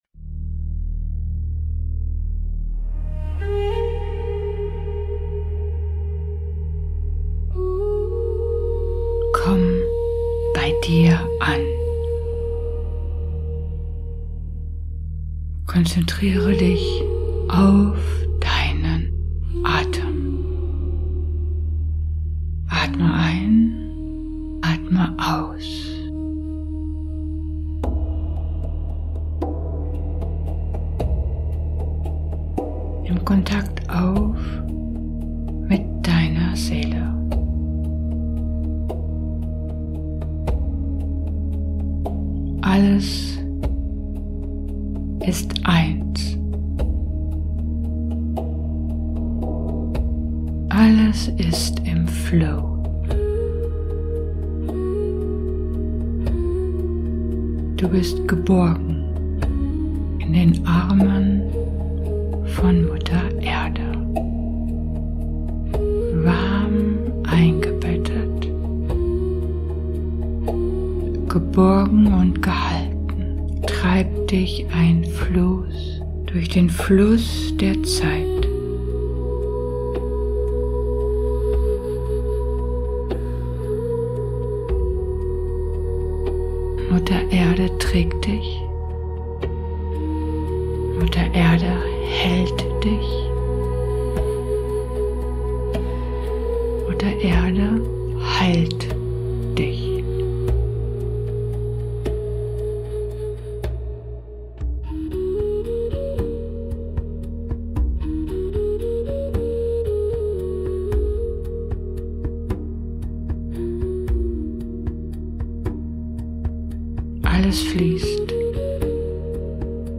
Mantra Meditation